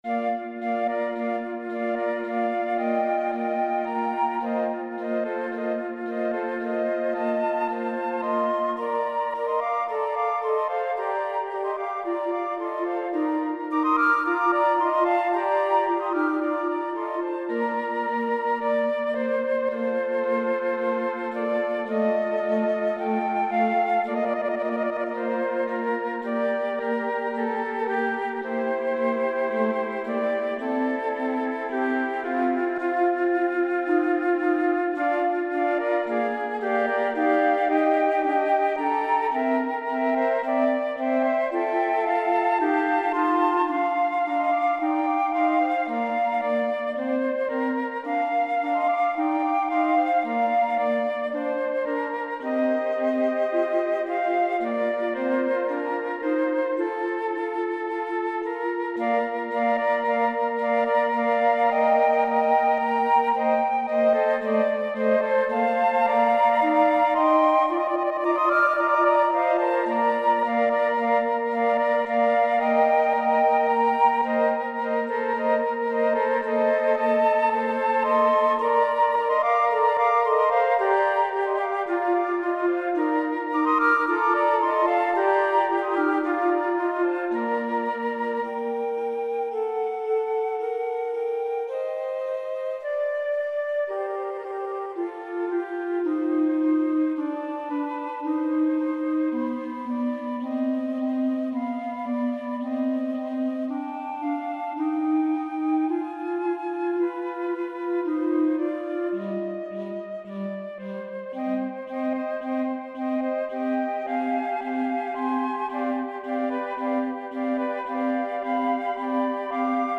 für 4 Flöten (4. Flöte Altflöte in G)